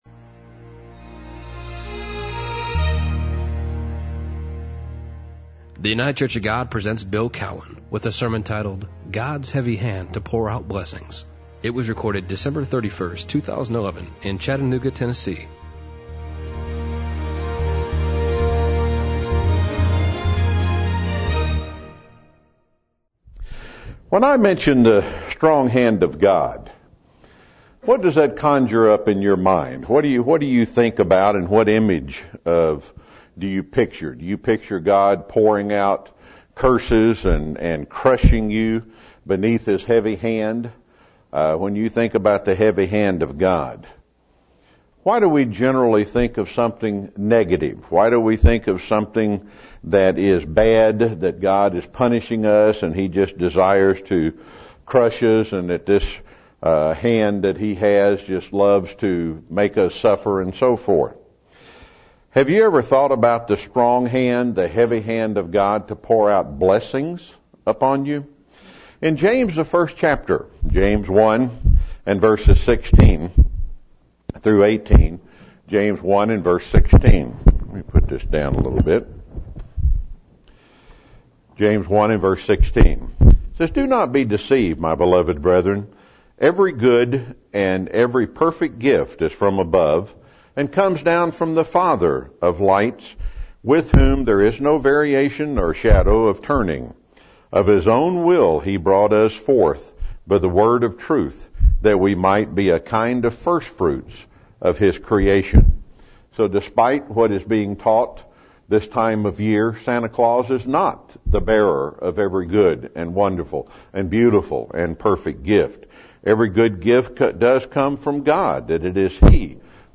Given in Chattanooga, TN